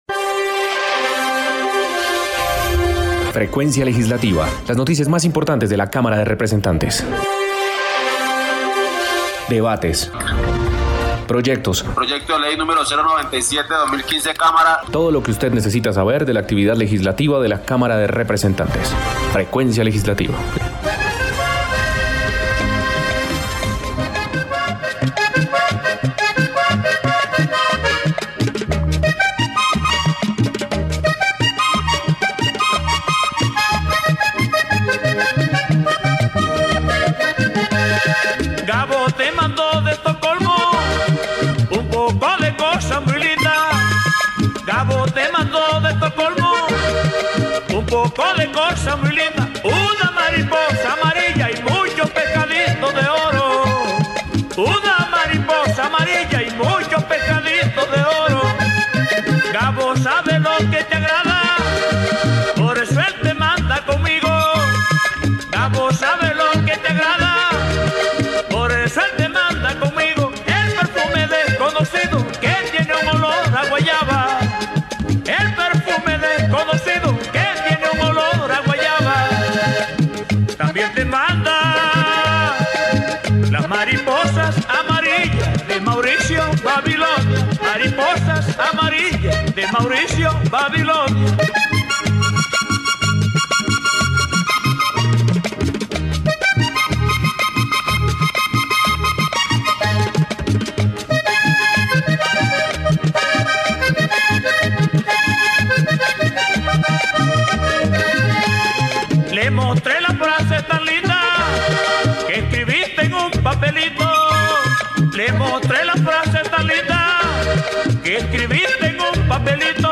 Programa Radial Frecuencia Legislativa. Domingo 6 de Marzo de 2022